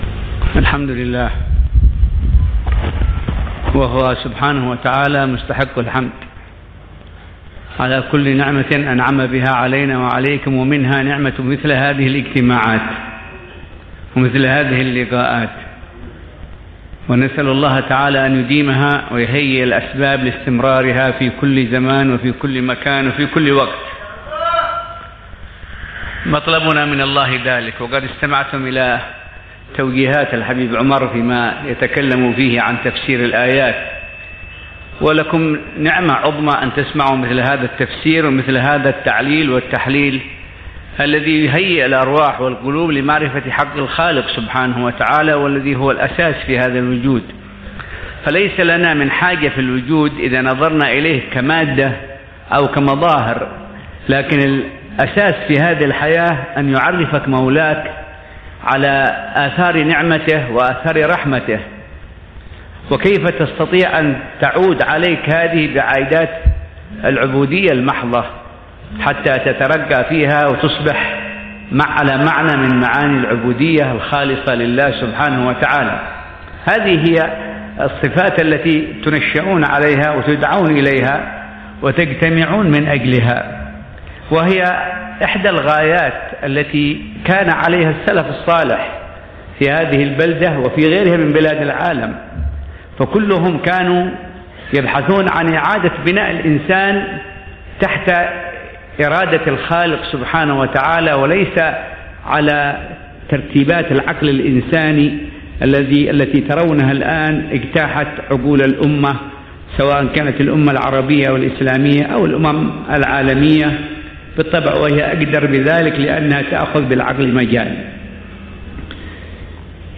كلمة في درس الإثنين بدار المصطفى مساء 3 محرم 1436هـ